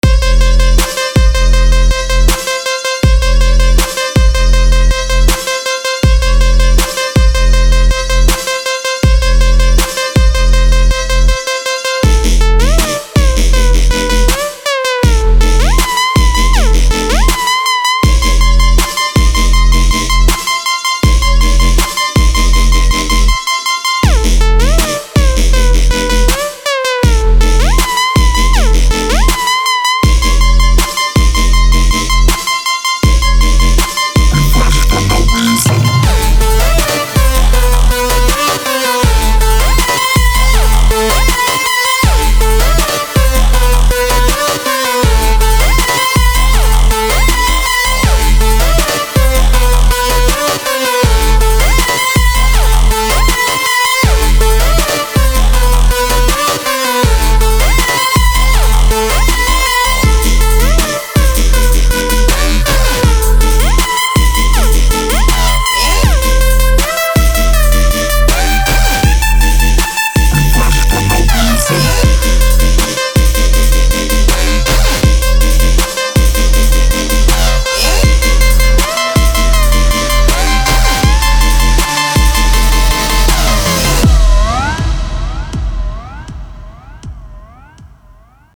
• Качество: 320, Stereo
без слов
звонкие